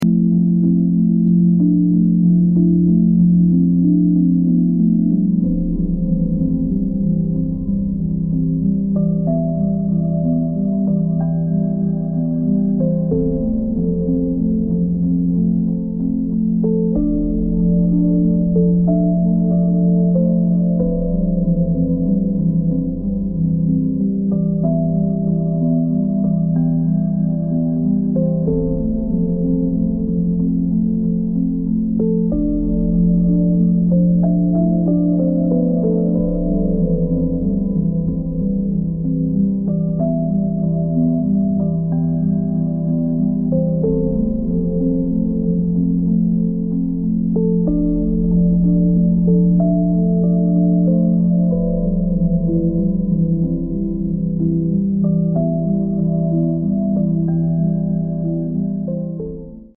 Healing Music for Inner Balance & Renewal